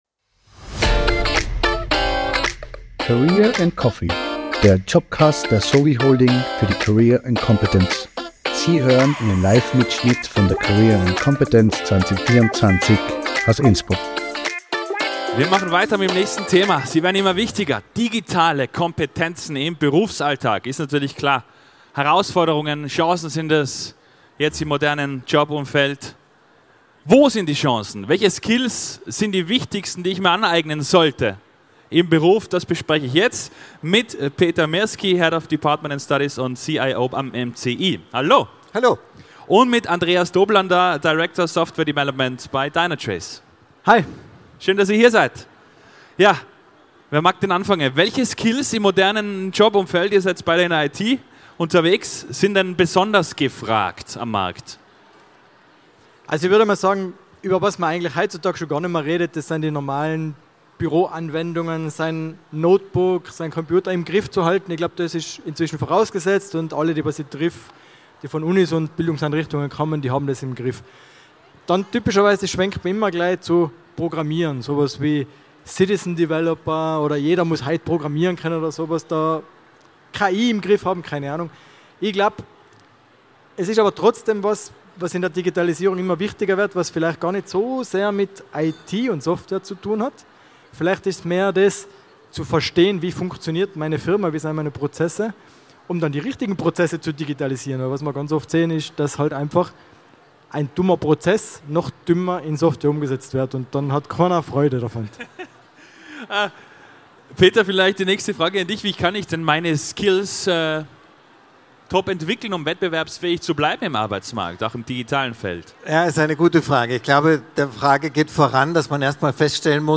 Expert:innen diskutieren in dieser Podcast-Folge, wie Deine Karriere von der Digitalisierung profitiert! Livemitschnitt von der career & competence am 24. April 2024 im Congress Innsbruck.